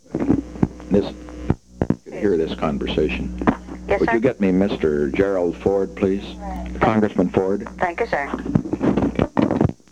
Secret White House Tapes
Location: White House Telephone
The President talked with the White House operator; the President conferred with an unknown
person in the background.